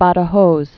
(bädə-hōz, -thä-hōth)